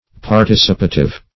Search Result for " participative" : The Collaborative International Dictionary of English v.0.48: Participative \Par*tic"i*pa*tive\ (p[aum]r*t[i^]s"[i^]*p[asl]*t[i^]v), a. [Cf. F. participatif.]
participative.mp3